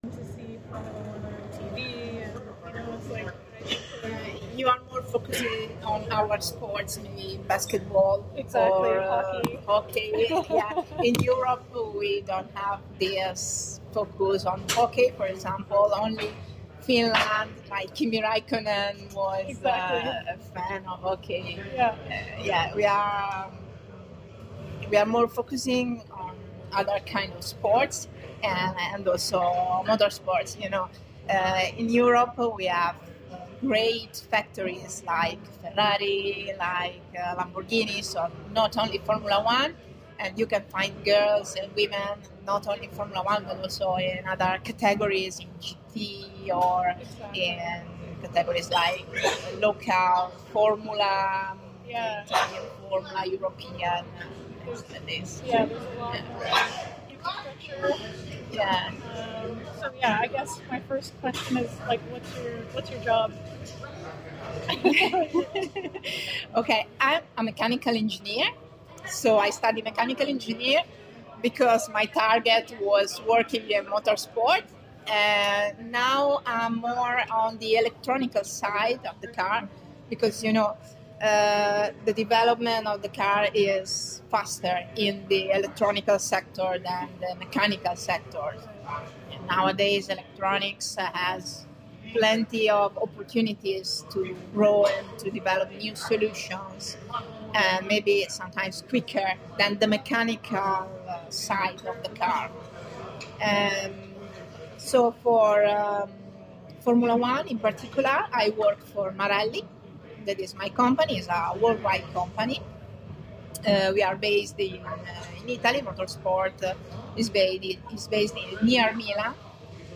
Interview Transcript: